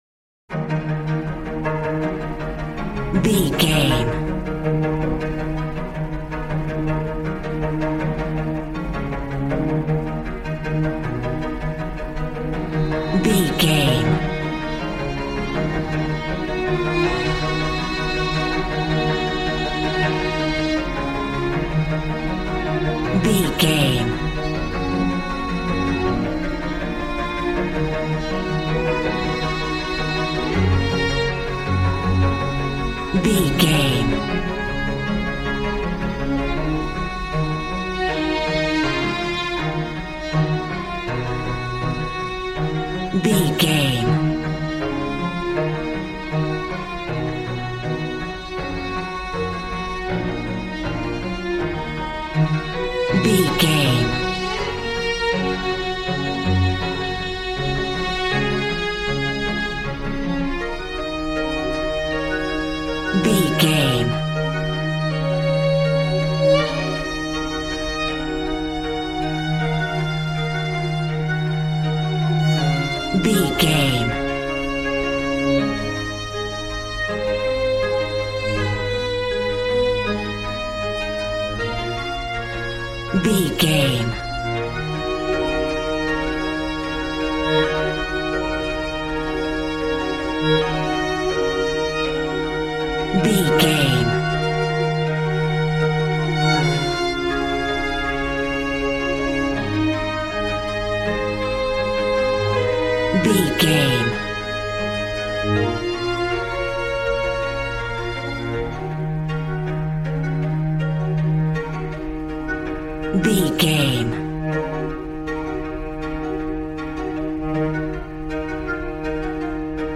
Modern film strings for romantic love themes.
Regal and romantic, a classy piece of classical music.
Aeolian/Minor
E♭
regal
cello
violin
brass